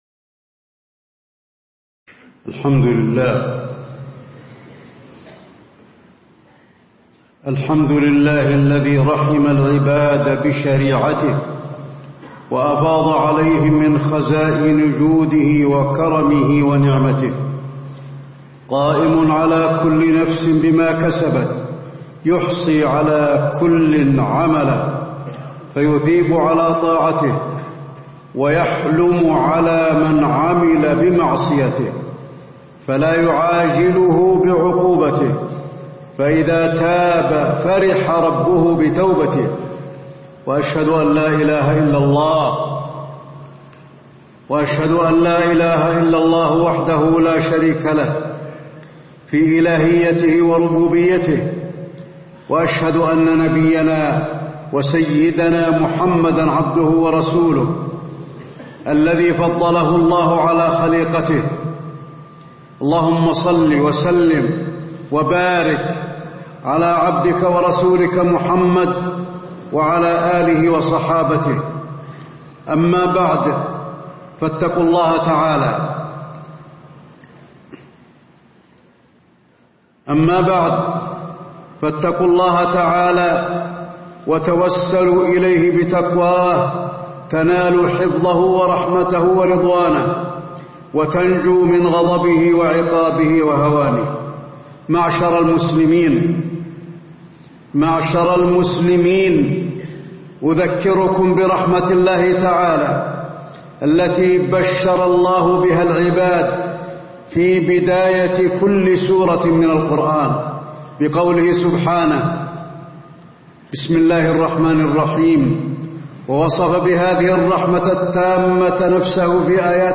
تاريخ النشر ٥ ربيع الثاني ١٤٣٧ هـ المكان: المسجد النبوي الشيخ: فضيلة الشيخ د. علي بن عبدالرحمن الحذيفي فضيلة الشيخ د. علي بن عبدالرحمن الحذيفي فضائل تطبيق شريعة الله تعالى وأحكامه